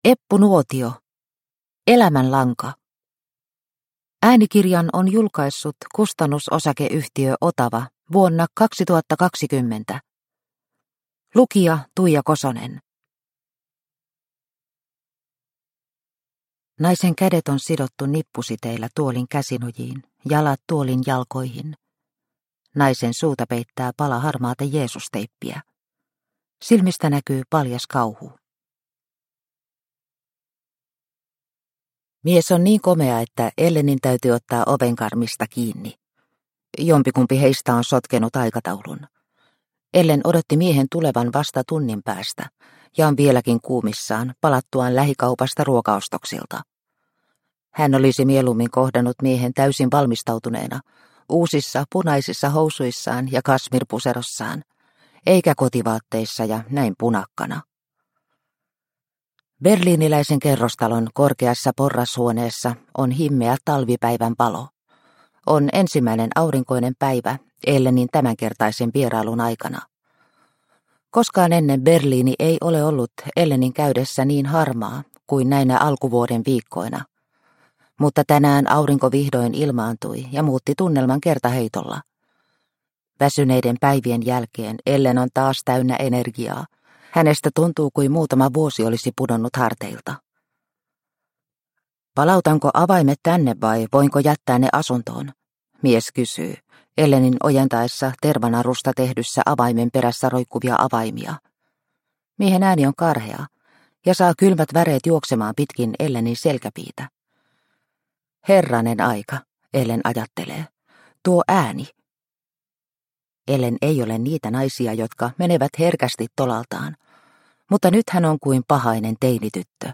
Elämänlanka – Ljudbok – Laddas ner